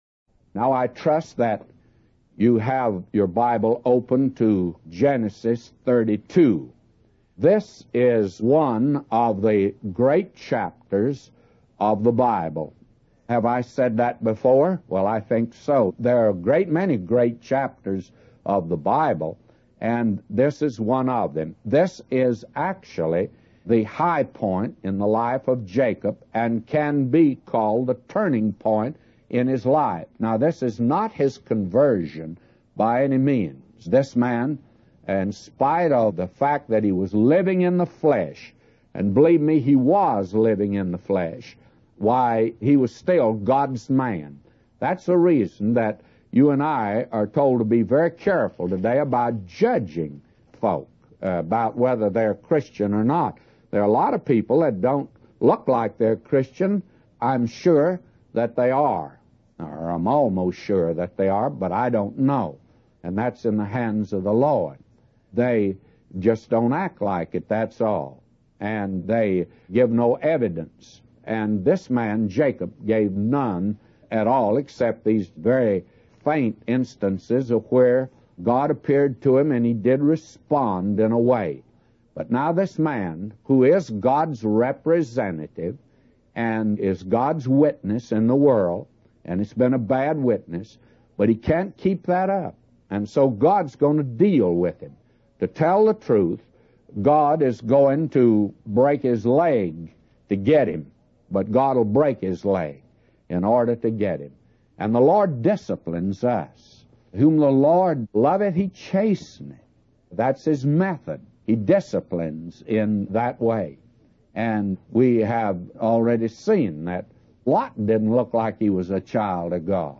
In this sermon, the preacher focuses on the life of Jacob and how God dealt with him.